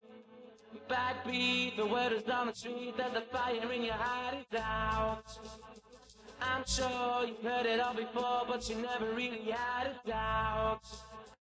Vocals Extracted Using Mimc